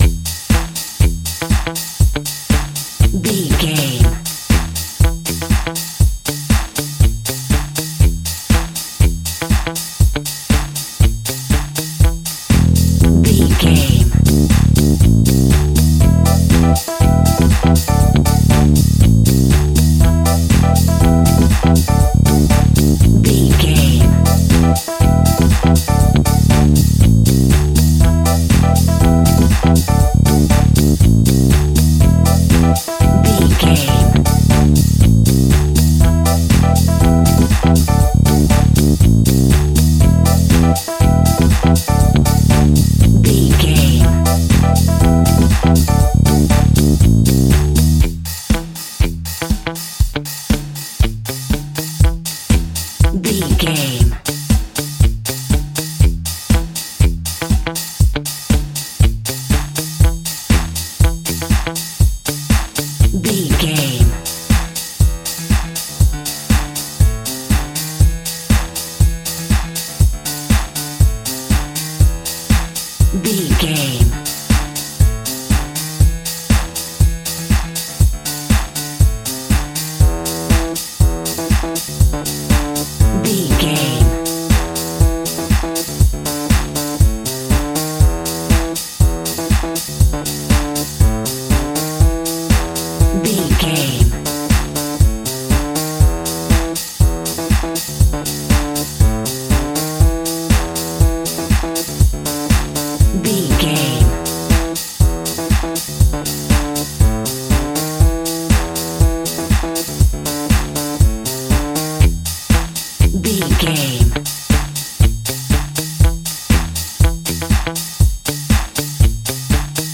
Aeolian/Minor
groovy
uplifting
energetic
drums
bass guitar
electric piano
synthesiser
disco house
electronic funk
bright
upbeat
synth leads
Synth Pads
synth bass
drum machines